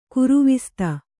♪ kuruvista